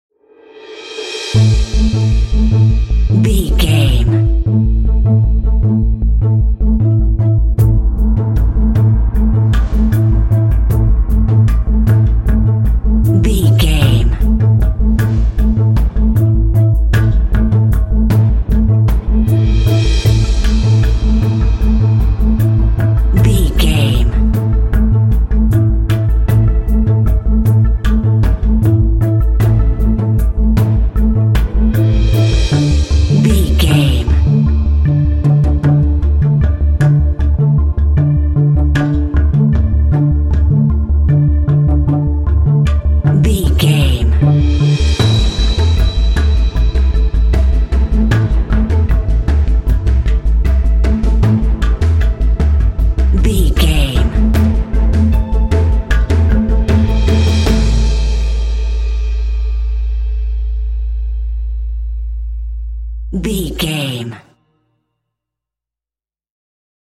Aeolian/Minor
tension
suspense
dramatic
contemplative
drums
strings
synthesiser
cinematic
film score